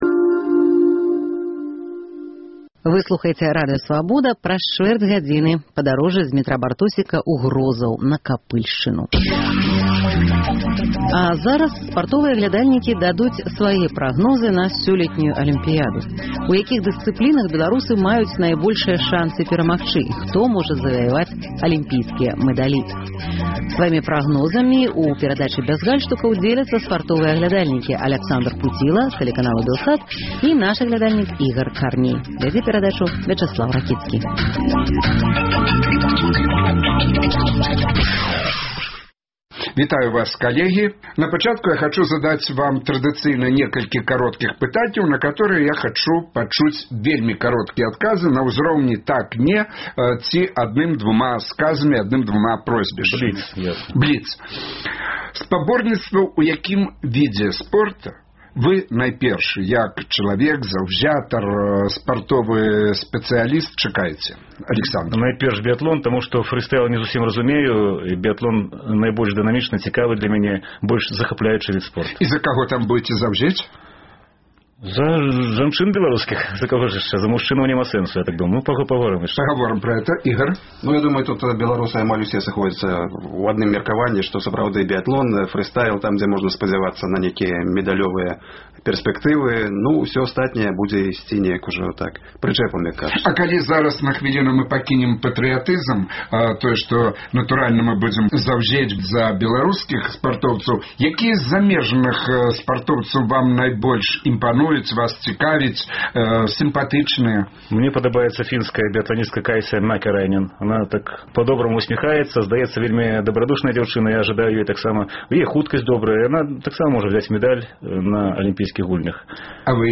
У якіх дысцыплінах беларусы маюць найбольшыя шанцы перамагчы? Хто можа заваяваць алімпійскія мэдалі? Сваімі прагнозамі дзеляцца спартовыя аглядальнікі